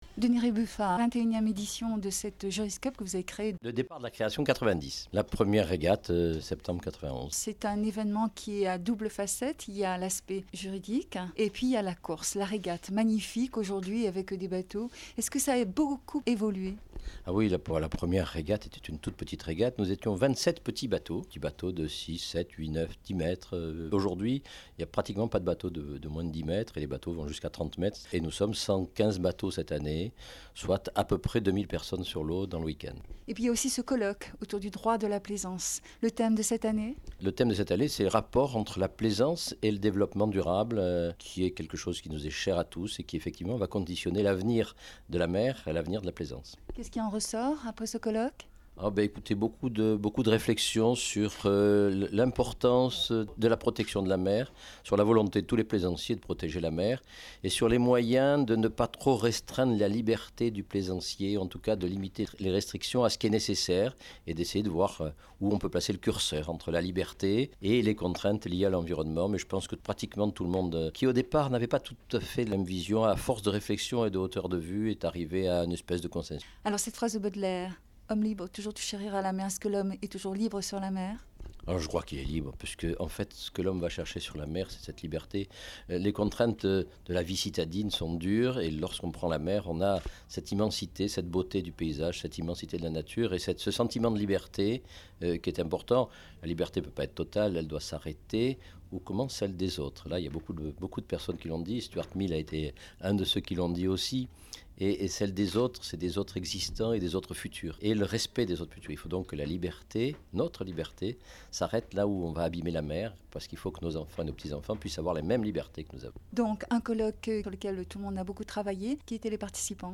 ITW au matin de la première régate.